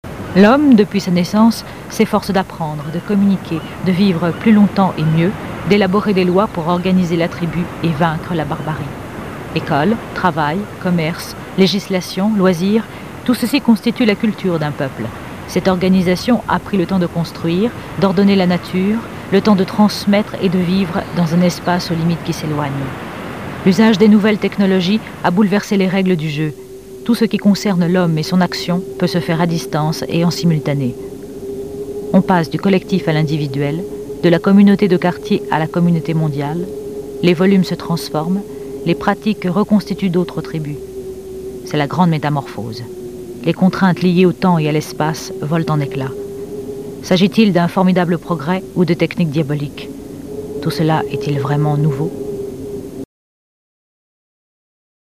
commentaire pour Arte
Voix off